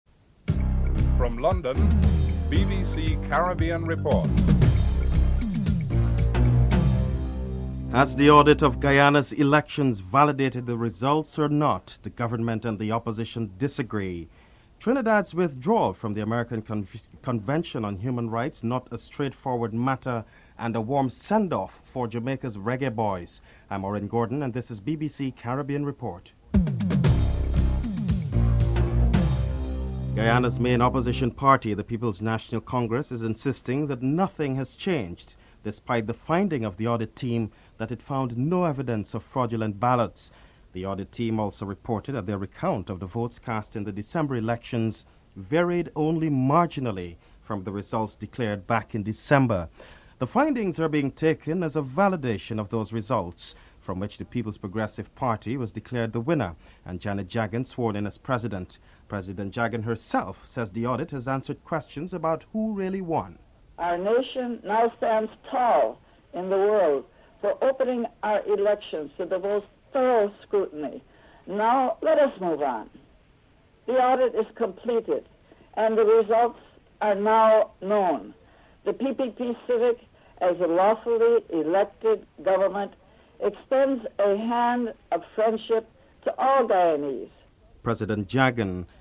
Interview with Opposition Leader Desmond Hoyte (11:37-15:15)